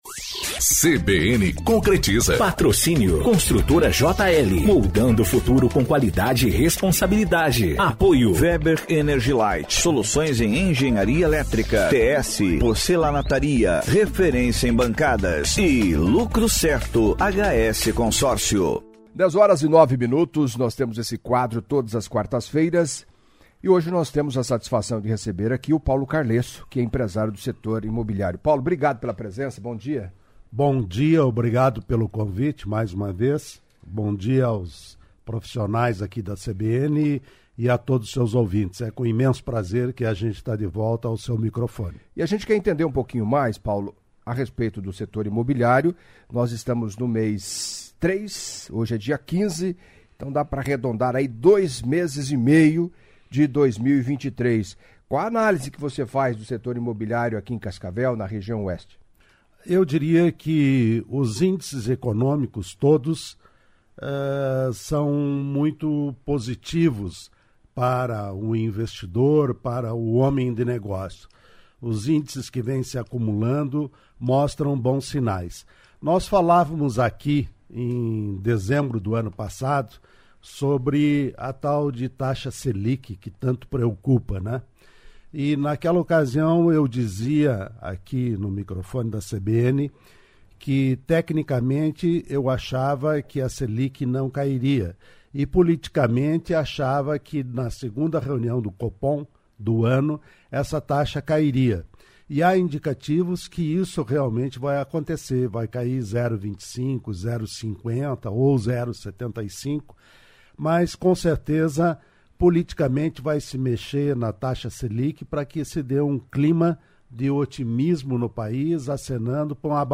Em entrevista à CBN Cascavel nesta quarta-feira